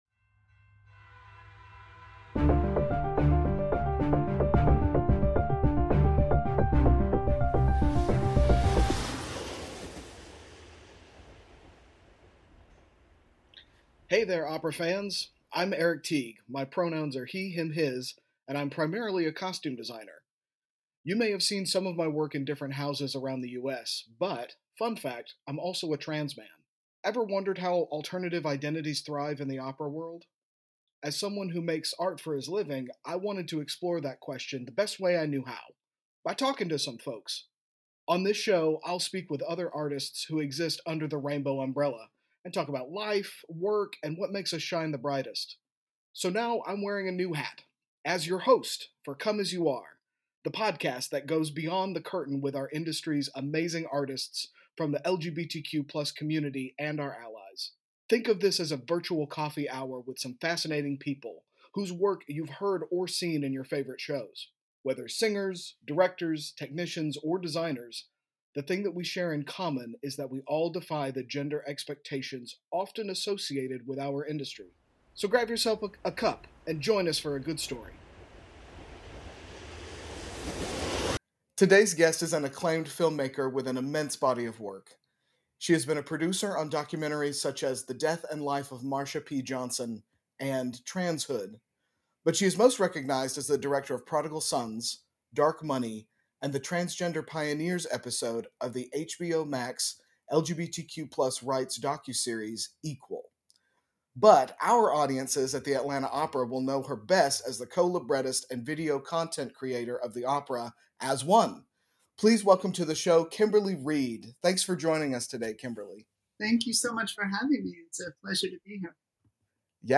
Our final conversation of the season